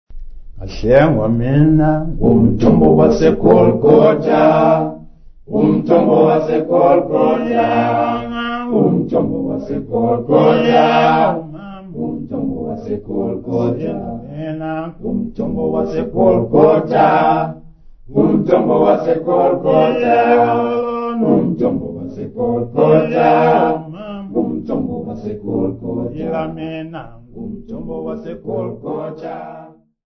Folk music
Sacred music
Field recordings
Africa South Africa Lady Frere, Eastern Cape sa
Church choral hymn with unaccompanied singing. New Zulu Church service hymn.